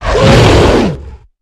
hit2.ogg